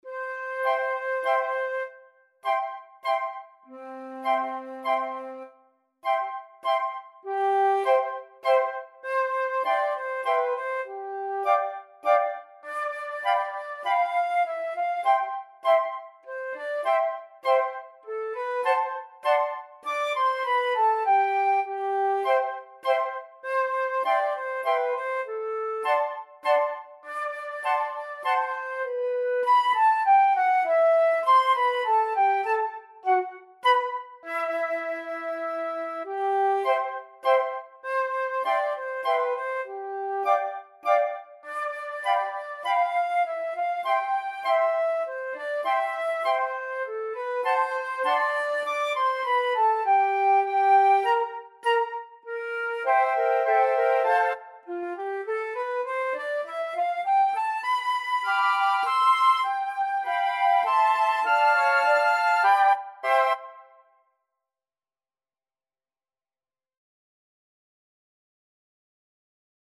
Flute 1Flute 2Flute 3Flute 4/Bass Flute
3/4 (View more 3/4 Music)
Slowly = c.100
Flute Quartet  (View more Easy Flute Quartet Music)